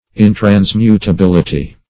Search Result for " intransmutability" : The Collaborative International Dictionary of English v.0.48: Intransmutability \In`trans*mu`ta*bil"i*ty\, n. The quality of being intransmutable.
intransmutability.mp3